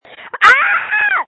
Screams from January 22, 2021
• When you call, we record you making sounds. Hopefully screaming.